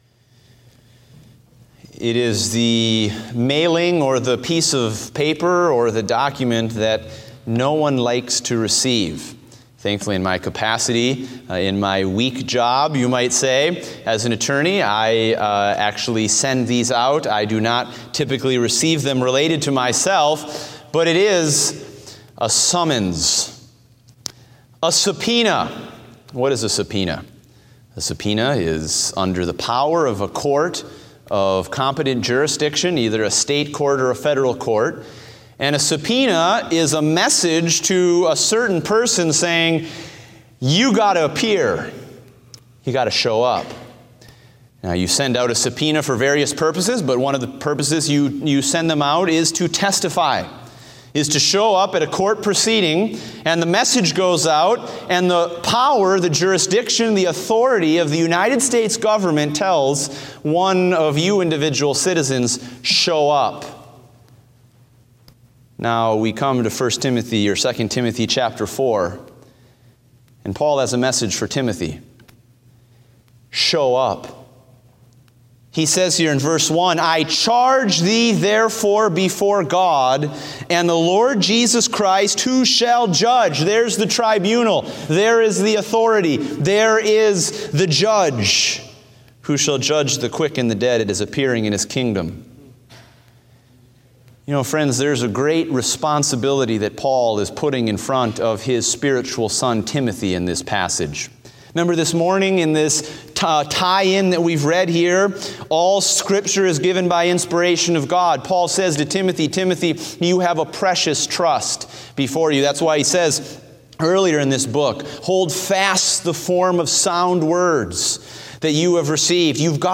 Date: June 26, 2016 (Evening Service)